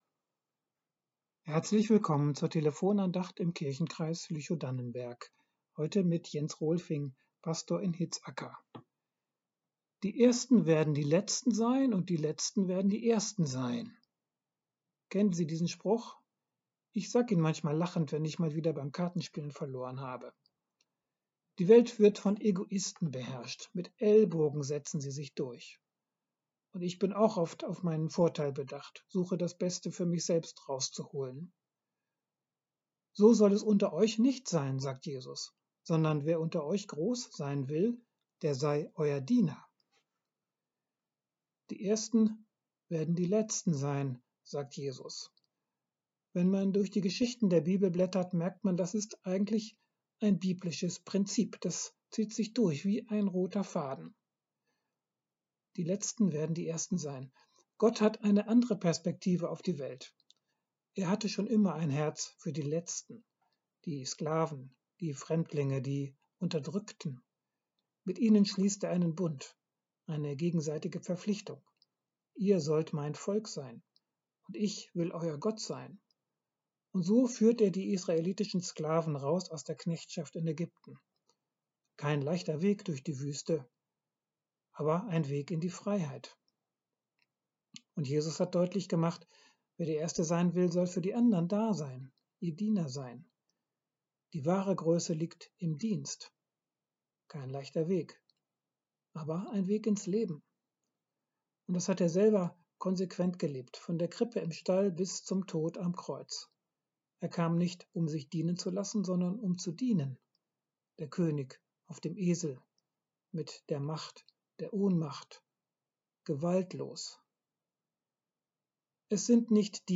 Die Letzten werden die Ersten sein ~ Telefon-Andachten des ev.-luth. Kirchenkreises Lüchow-Dannenberg Podcast